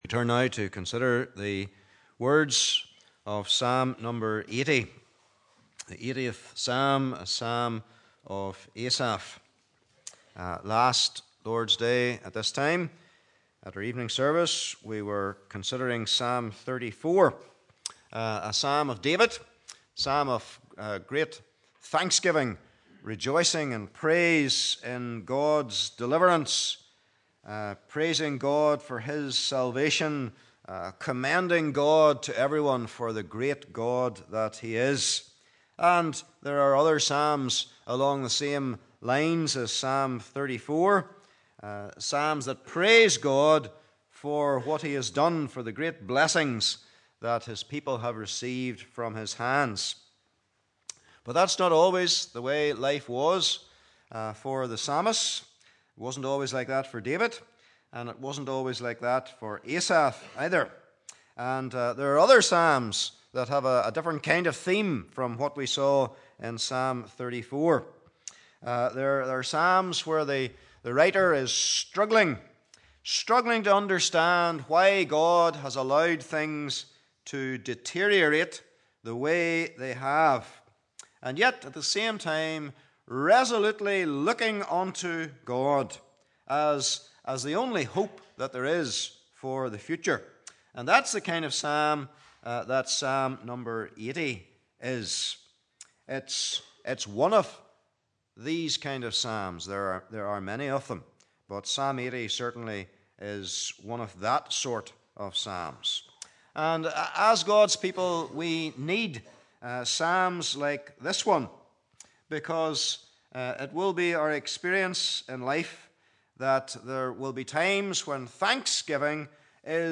Service Type: Evening Service